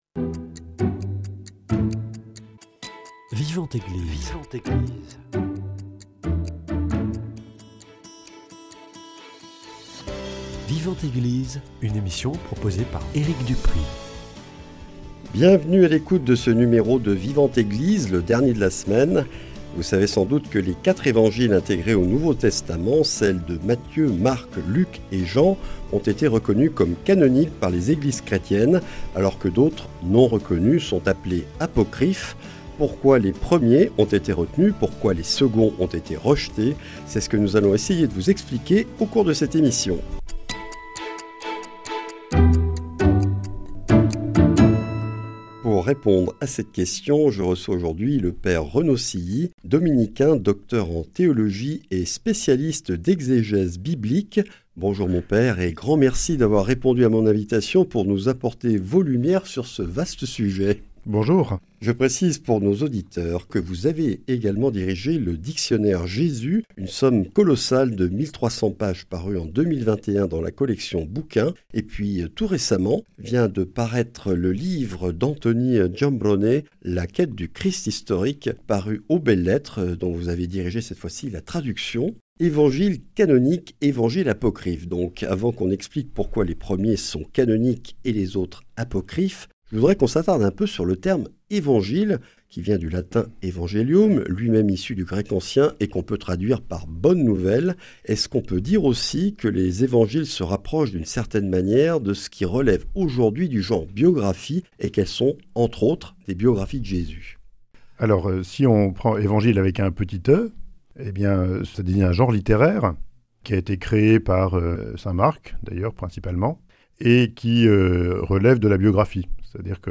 [ Rediffusion ]